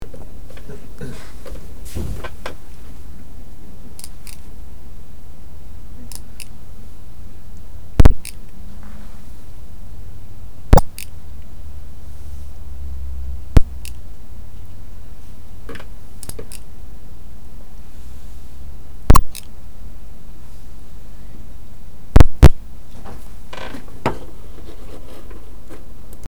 Phantom Noise
Am PC konnte ich eine Aufnahem machen...